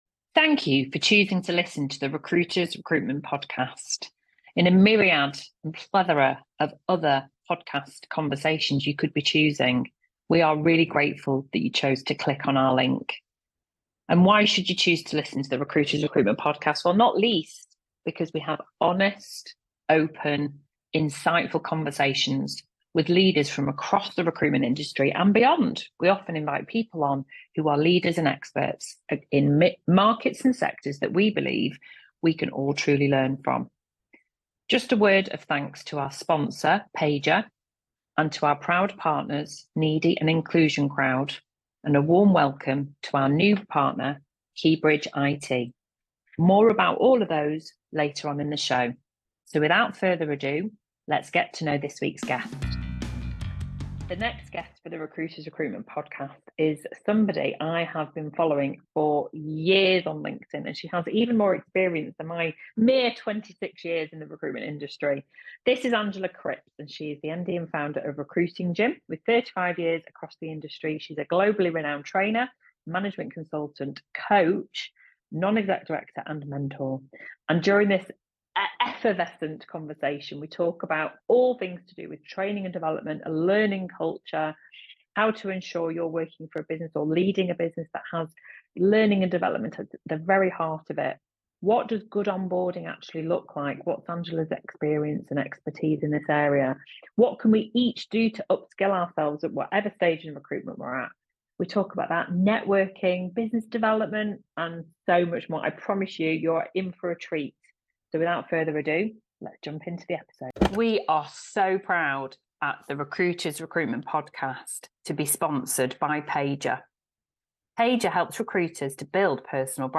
Join us for an enlightening conversation